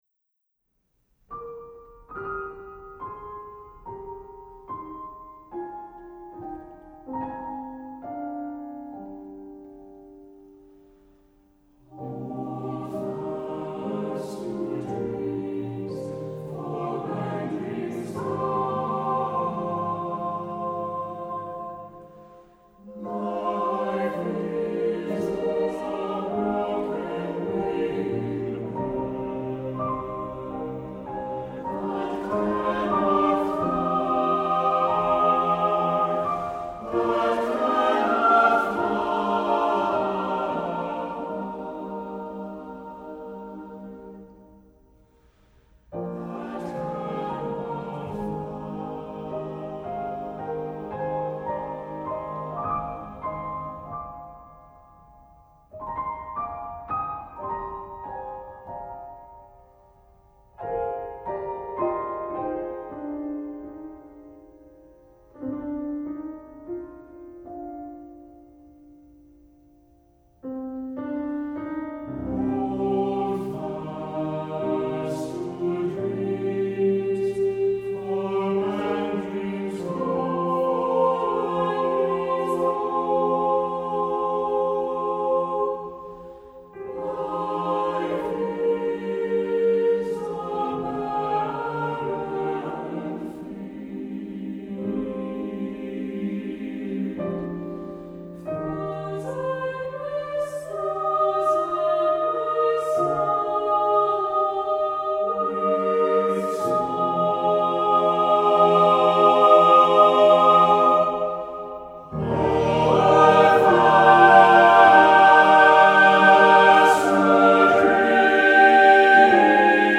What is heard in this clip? Voicing: "SSAATTBB"